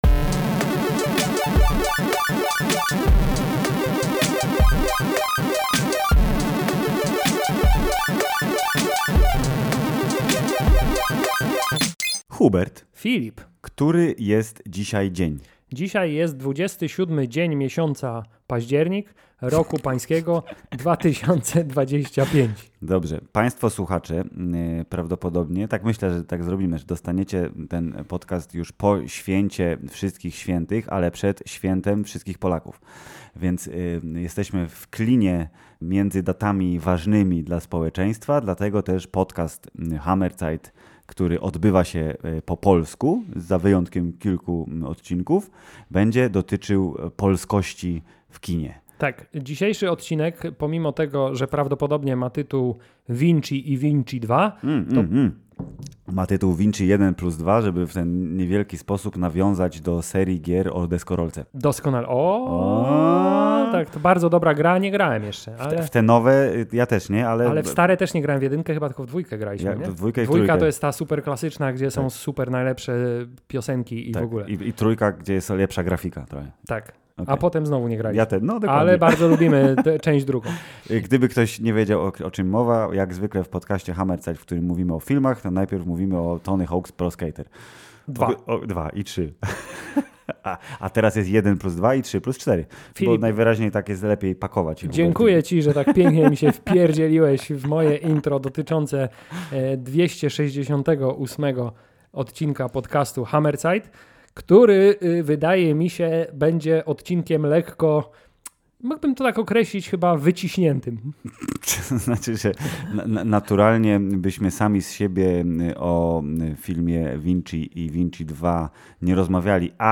dwóch czterdziestolatków, lubią opowiadać o obejrzanych filmach i serialach.
… continue reading 427 episoder # Popkultura # Społeczeństwo # Polski # Film # Filmy # Seriale # Recenzje # Rozmowy # Kino # Serial # Rozrywka # Hammerzeit # Polsku